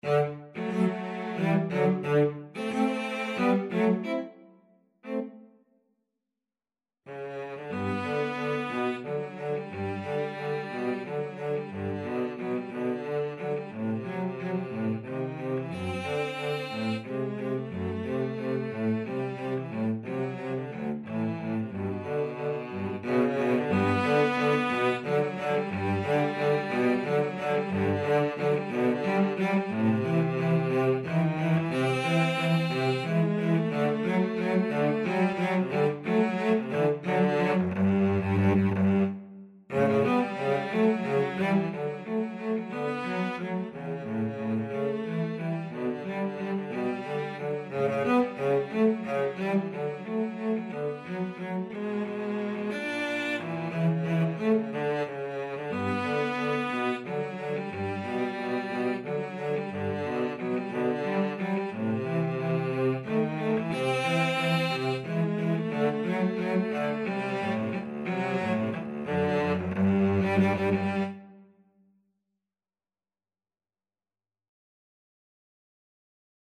Free Sheet music for Cello Duet
G major (Sounding Pitch) (View more G major Music for Cello Duet )
~ = 180 Tempo di Valse
3/4 (View more 3/4 Music)
Classical (View more Classical Cello Duet Music)